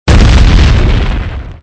ExplodeLarge.wav